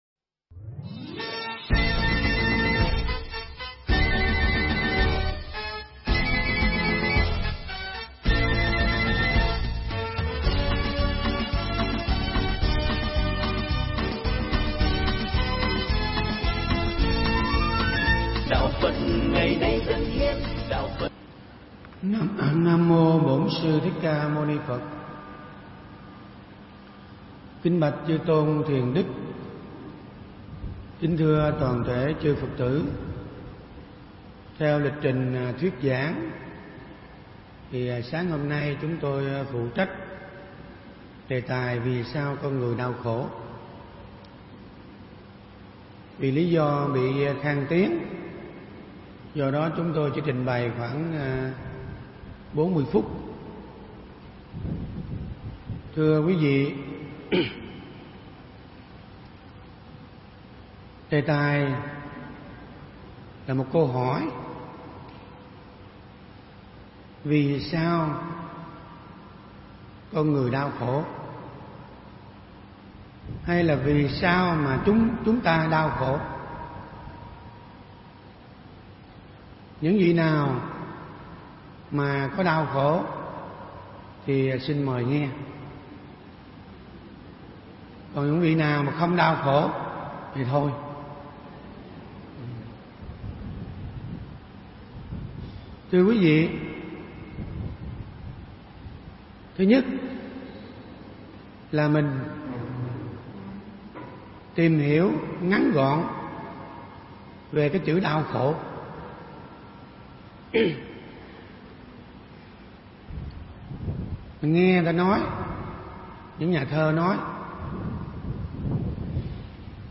Nghe Mp3 thuyết pháp Vì Sao Con Người Đau Khổ